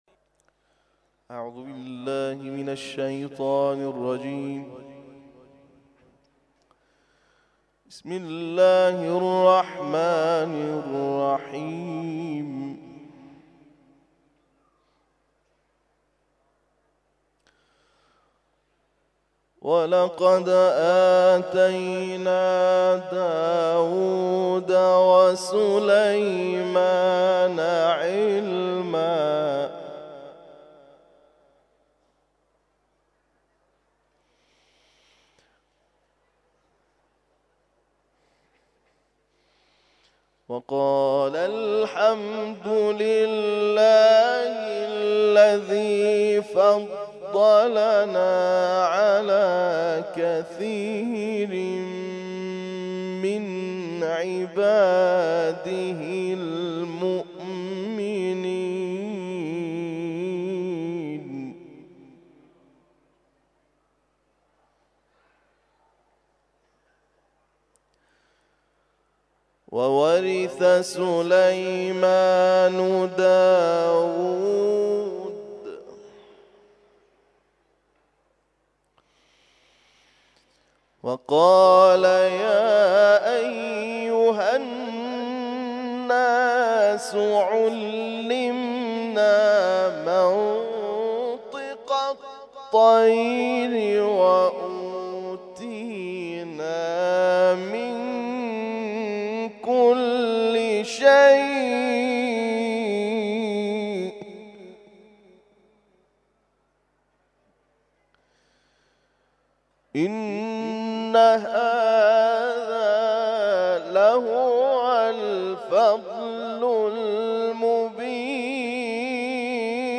تلاوت ظهر - سوره نمل آیات ( ۱۵ الی ۲۱ ) Download